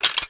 reload_clip.ogg